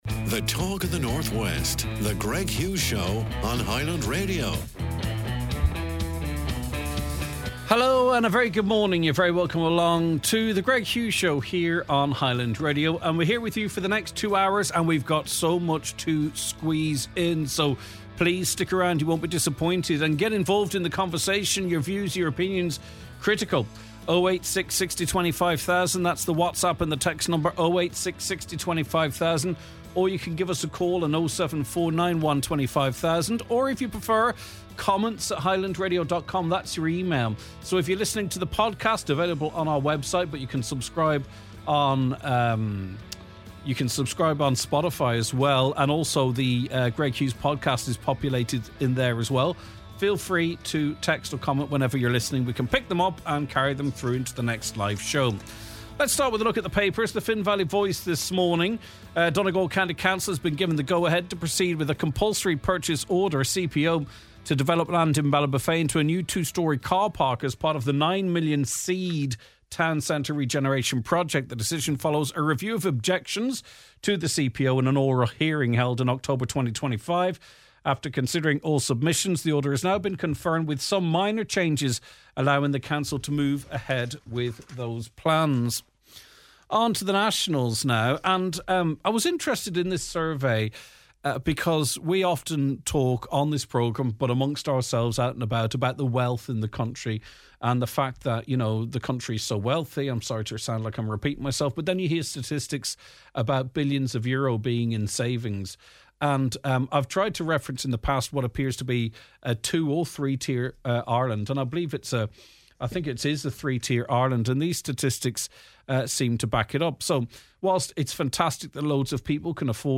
We talk to listeners and bus operators who say the current timetables simply aren’t enough.
Eoghan Quigg on Men’s Health X Factor star Eoghan Quigg joins us in studio.